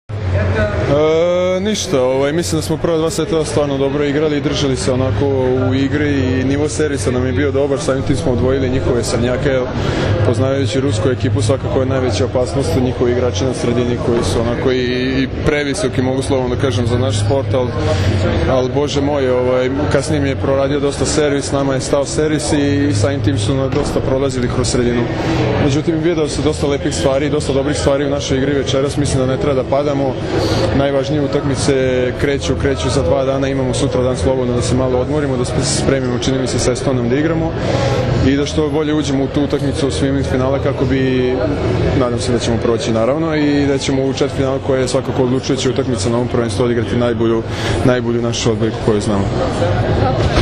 IZJAVA MARKA PODRAŠČANINA